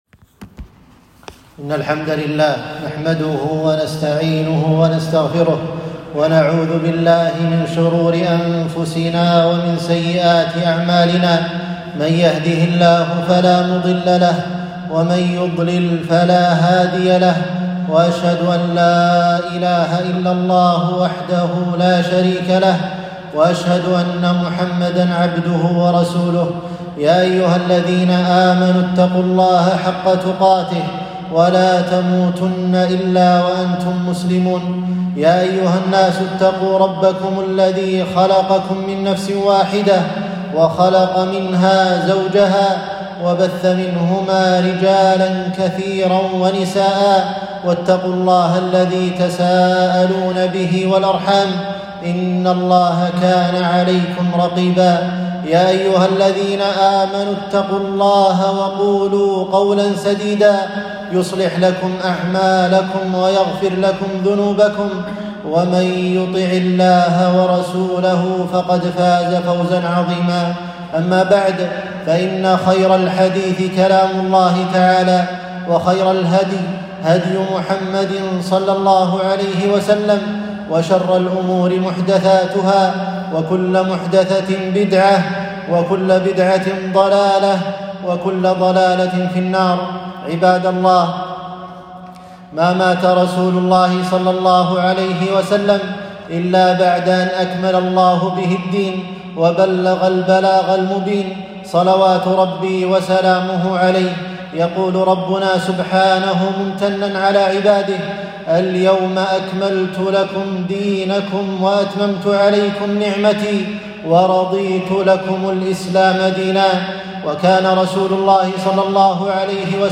خطبة - خطورة البدع والاحتفال بالمولد النبوي